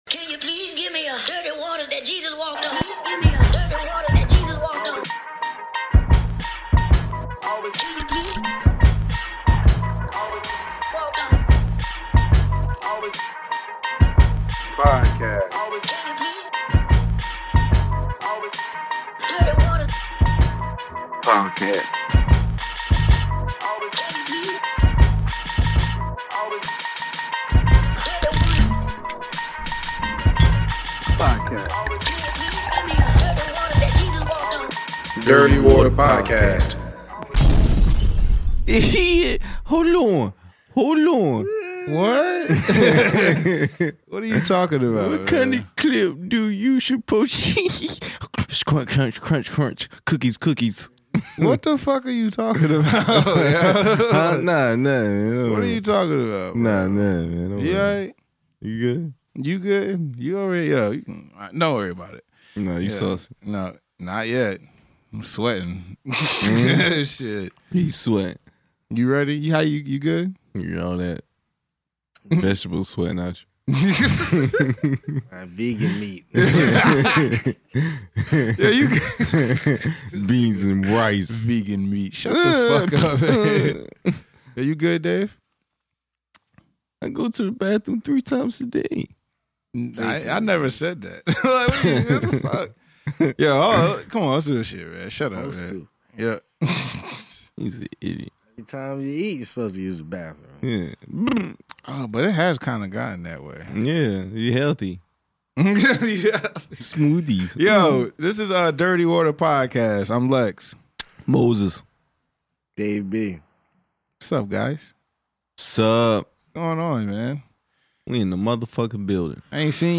The trio are back another week to discuss a few things!!